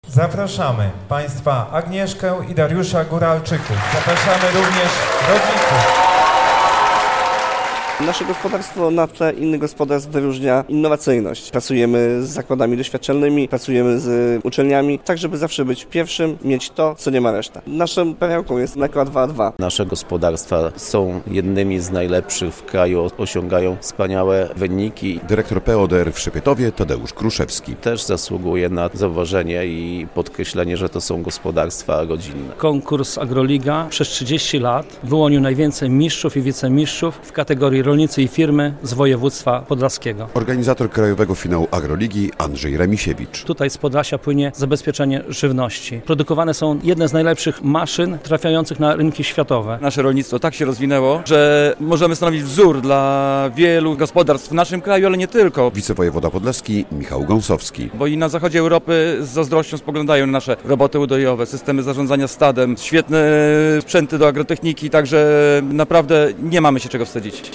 Podlaska Agroliga 2025 -relacja